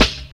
Snare (Go With The Flow).wav